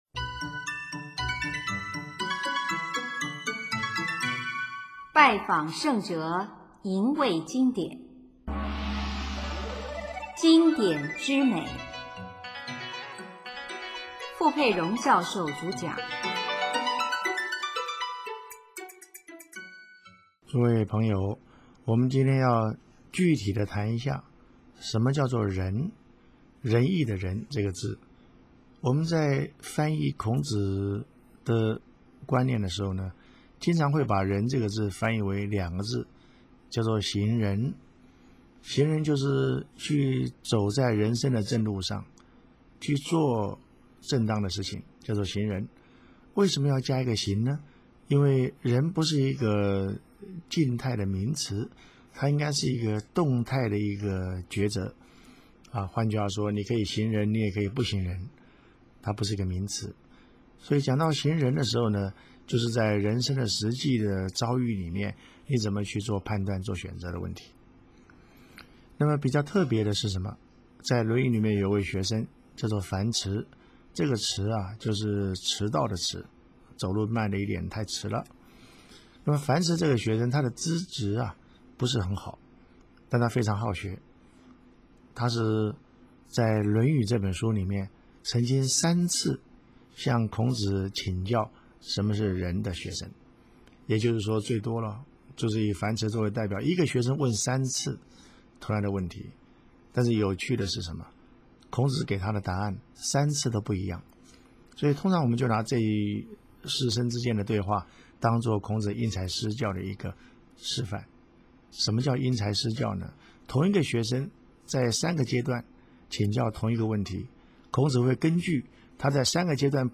主讲：傅佩荣教授